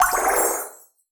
water_bubble_spell_heal_02.wav